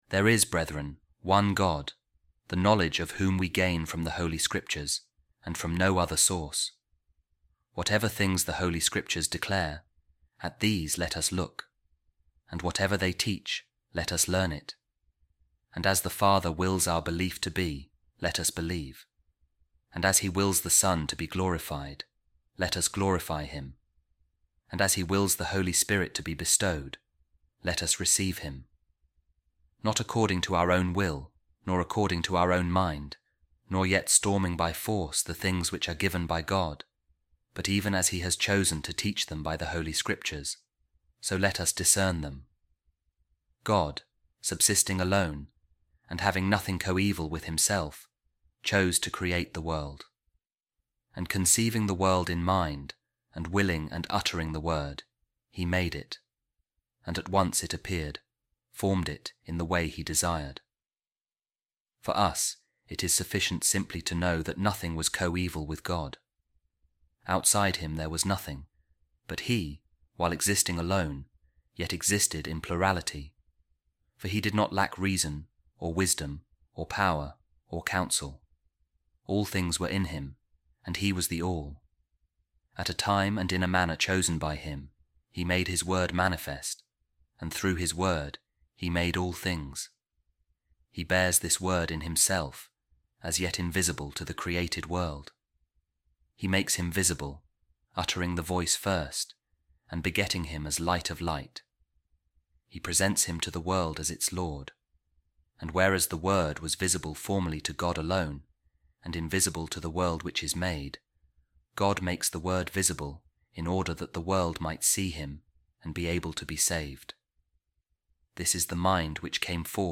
A Reading From The Treatise Of Saint Hippolytus Against The Heresy Of Noetus | The Hidden Sacrament Is Revealed